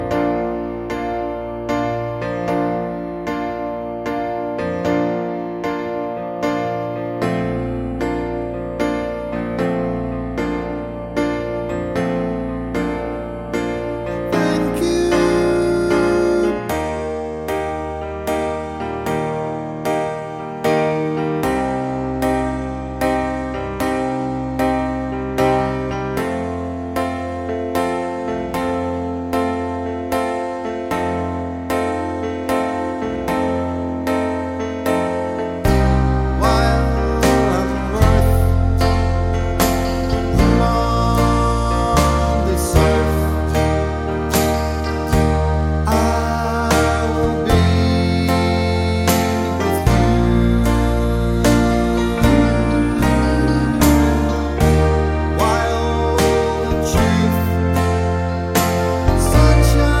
for duet Pop (1980s) 4:35 Buy £1.50